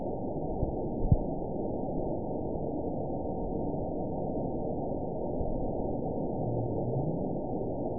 event 922715 date 03/17/25 time 23:16:08 GMT (1 month, 2 weeks ago) score 8.29 location TSS-AB04 detected by nrw target species NRW annotations +NRW Spectrogram: Frequency (kHz) vs. Time (s) audio not available .wav